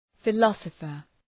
Προφορά
{fı’lɒsəfər}